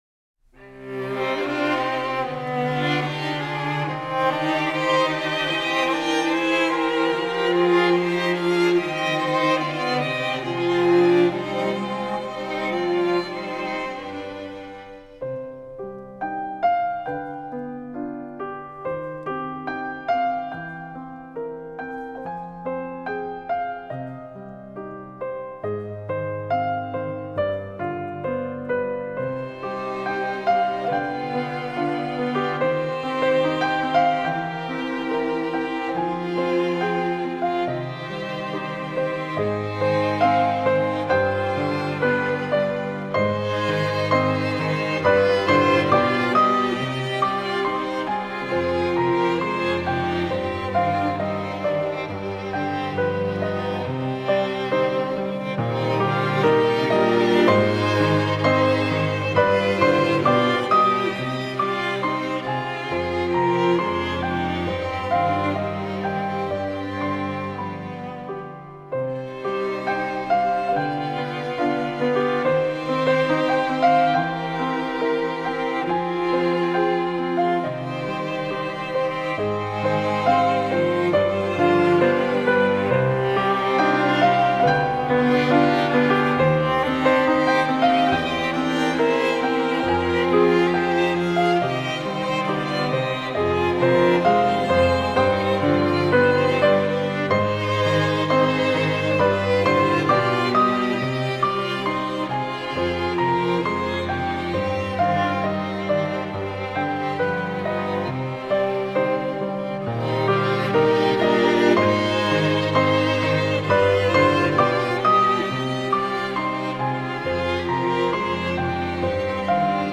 ( Versione 🎻 + 🎹 )